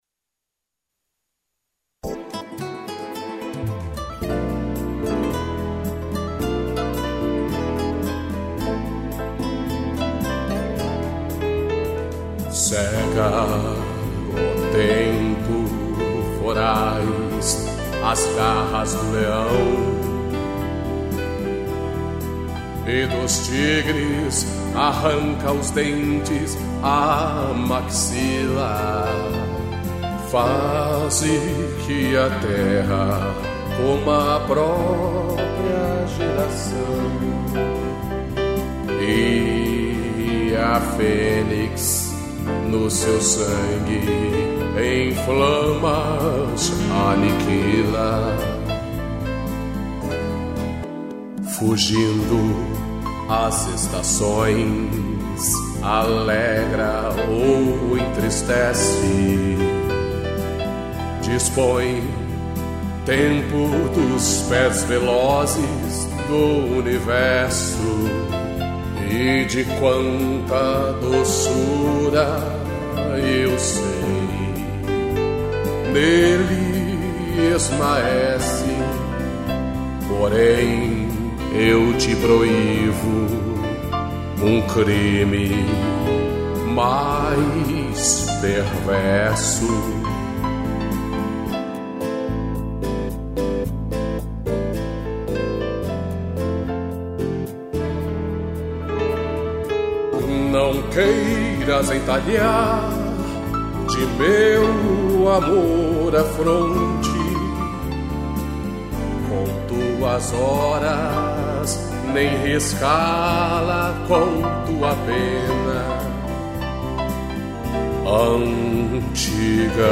voz e violão